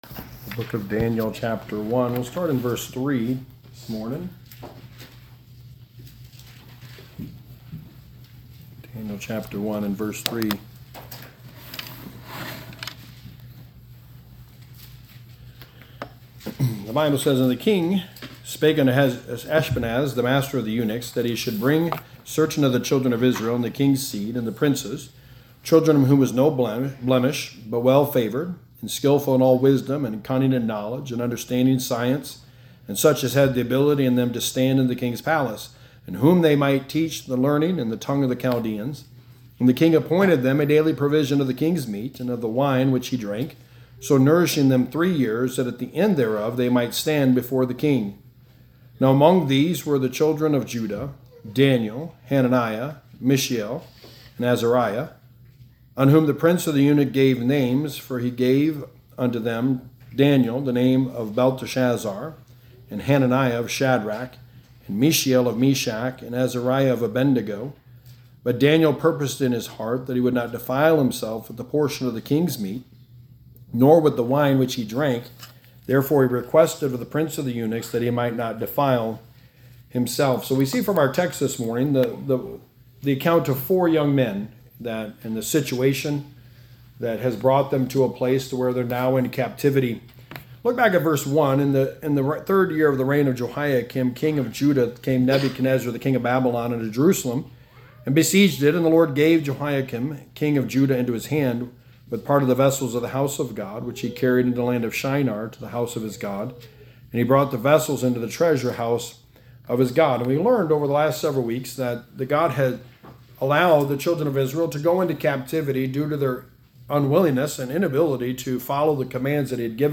Sermon #3: A Purposed Life – Trusting God in the Circumstances of Life
Service Type: Sunday Morning